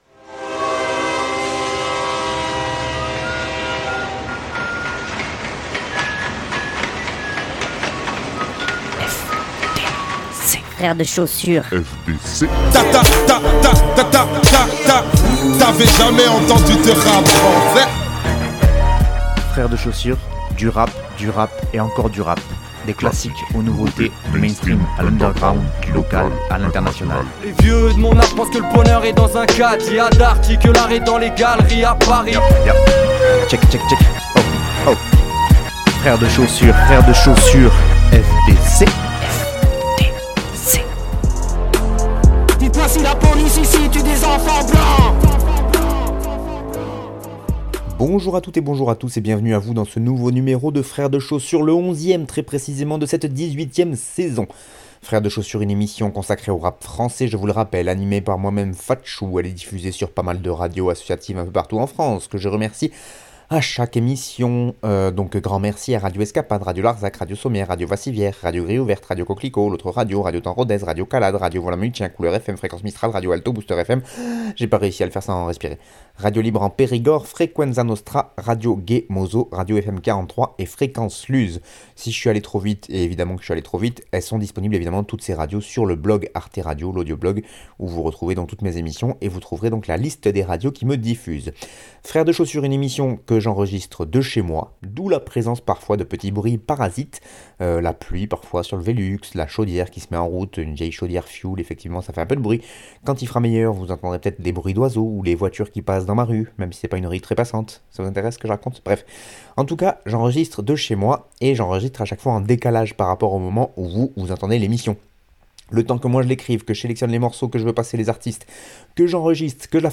c'est une émission sur le rap français